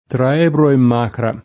Magra [ m #g ra ]